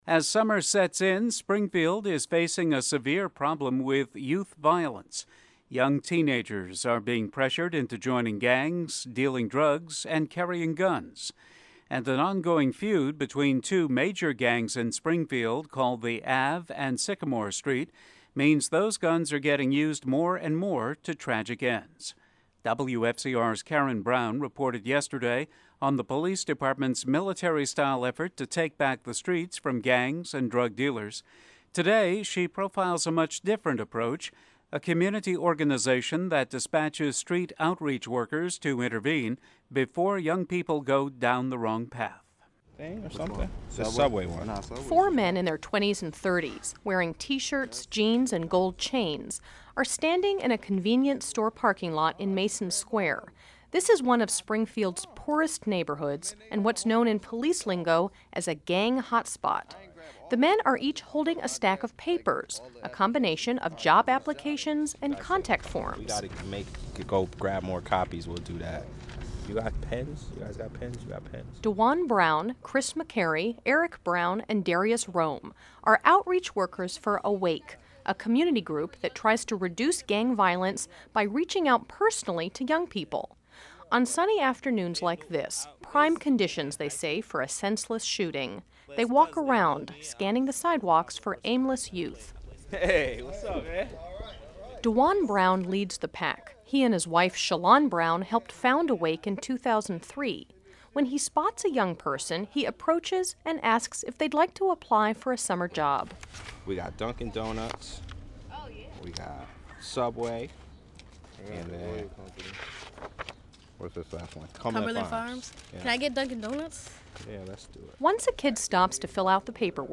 Aired on WFCR, May 2008